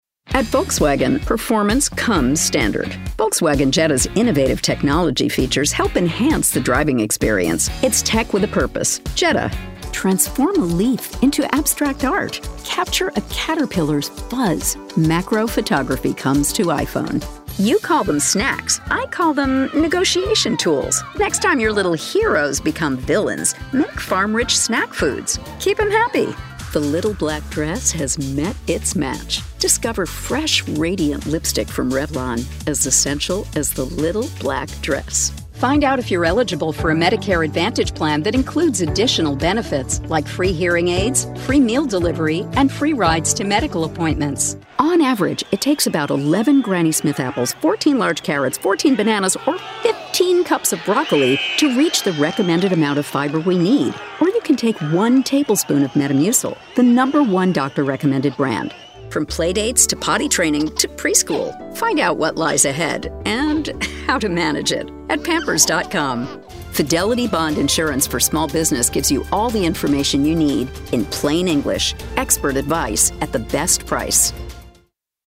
I'm a non-union New York-based VO talent, and I have a broadcast quality home studio.